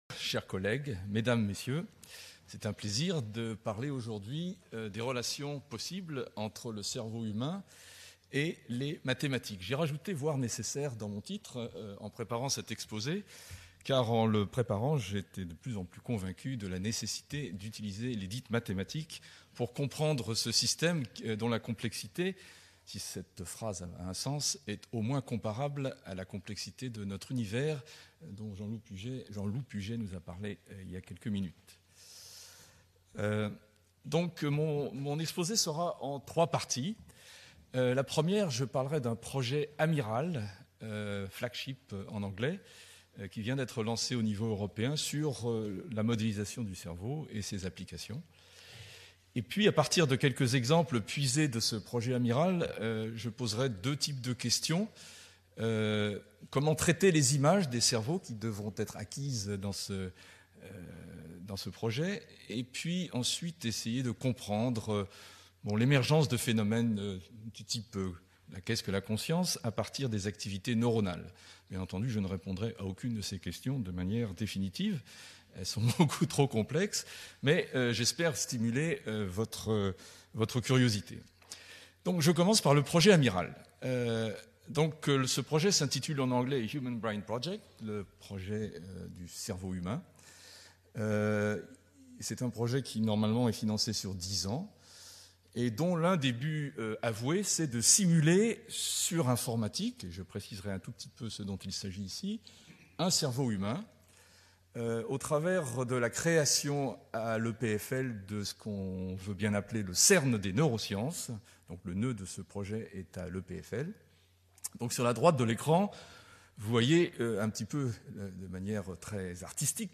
Investir un domaine émergent : les neurosciences statistiques Conférence donnée dans le cadre de la journée Colloquium "spécial 30 ans" du centre Inria Sophia Antipolis-Mé